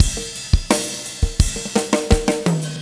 15 rhdrm85fill.wav